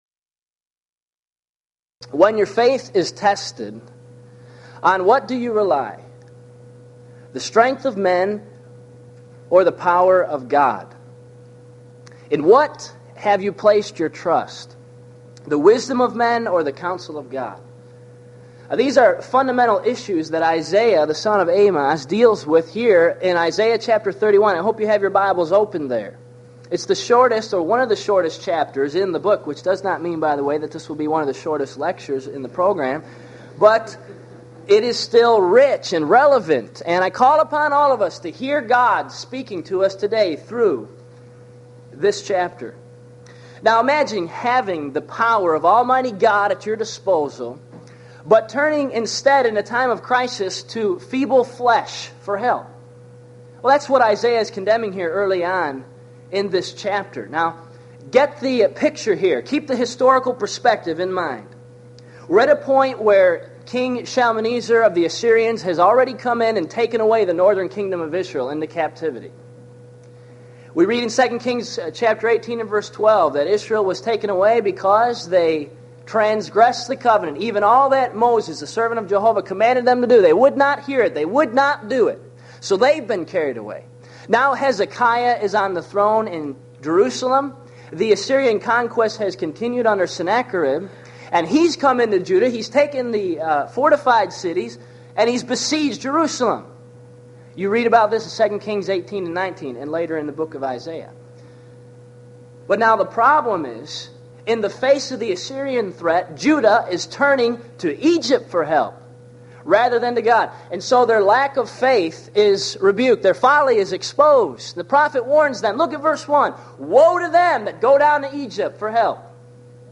Event: 1995 HCB Lectures Theme/Title: The Book Of Isaiah - Part I